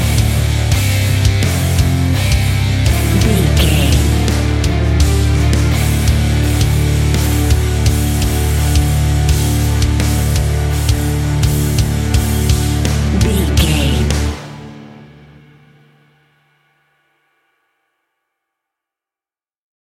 Epic / Action
Fast paced
Aeolian/Minor
hard rock
guitars
heavy metal
scary rock
horror rock
Heavy Metal Guitars
Metal Drums
Heavy Bass Guitars